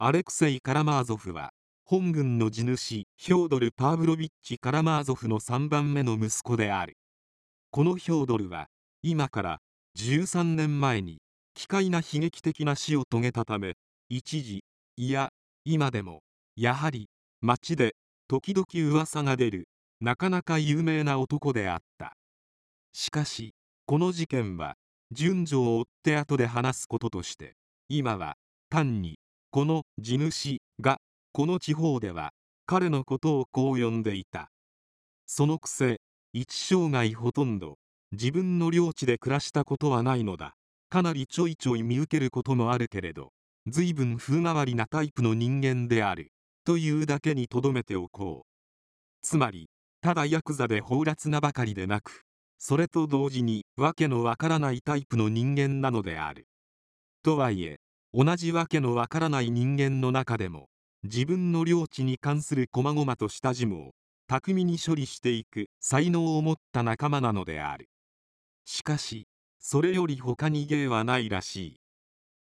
電子書籍や、音声朗読機能を使ってみるのも、良さそうなので、試しに作ってみました。
男性の声